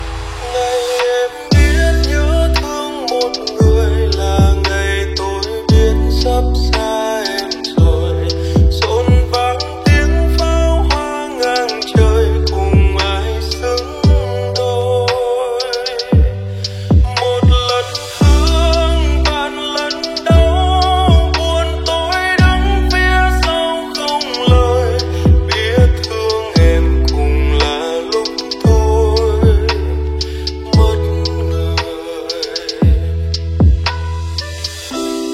Nhạc chuông 10 lượt xem 11/03/2026
Chạm Đến Trái Tim Với Âm Hưởng Lofi